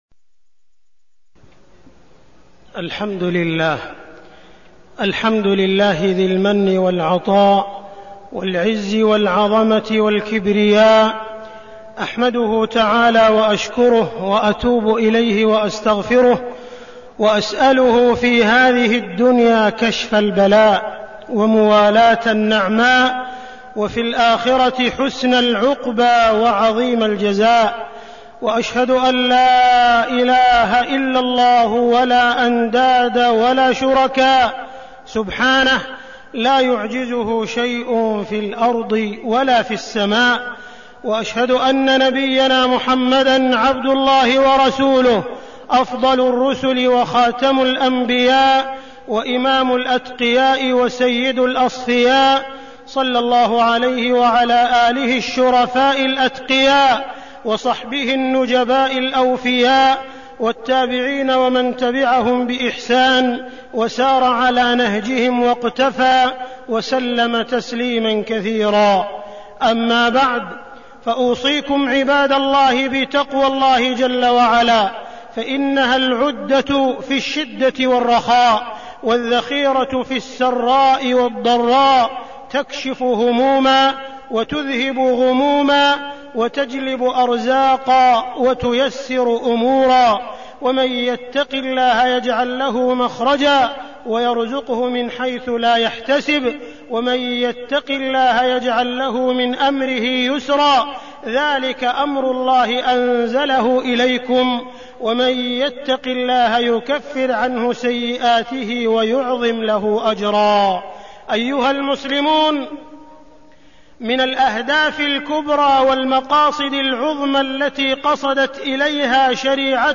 تاريخ النشر ٣ رجب ١٤١٩ هـ المكان: المسجد الحرام الشيخ: معالي الشيخ أ.د. عبدالرحمن بن عبدالعزيز السديس معالي الشيخ أ.د. عبدالرحمن بن عبدالعزيز السديس بناء الفرد الصالح